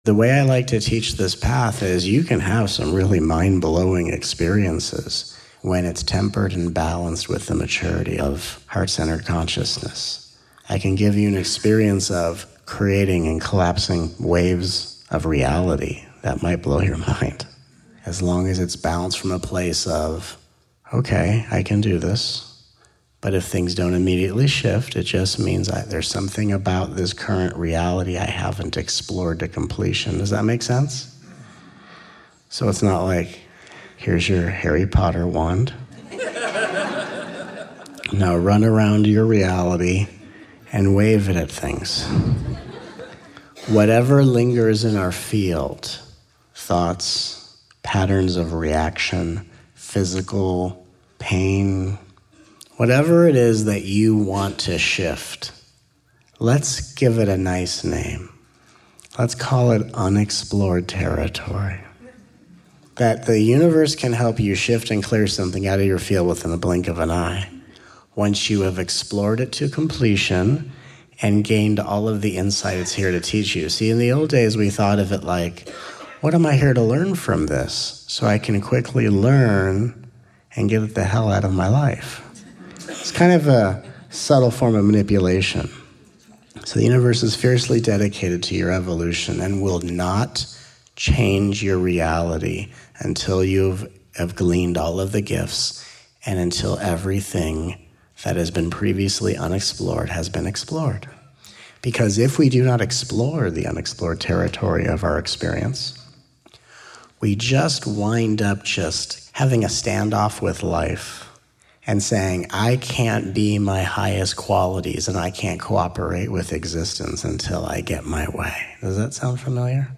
If so, join us for these 14 hours of nourishing, uplifting, and transformative highlights from the miraculous 5-day retreat.